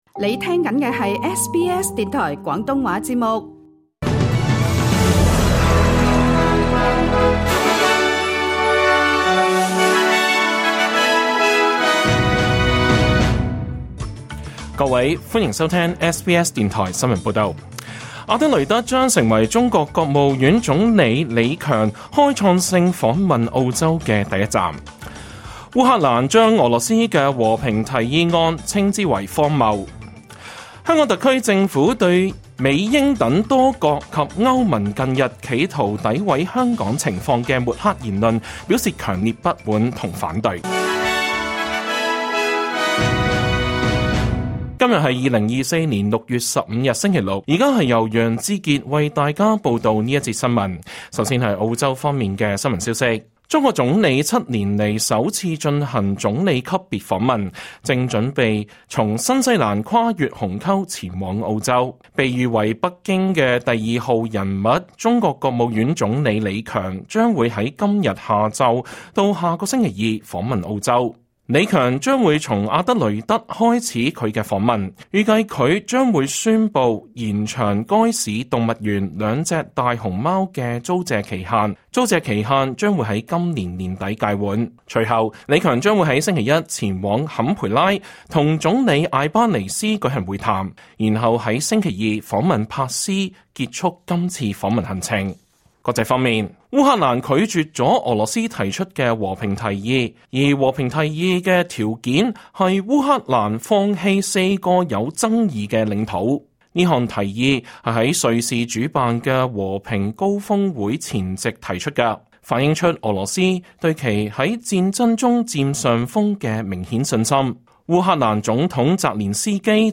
2024年6月15日 SBS 廣東話節目詳盡早晨新聞報道。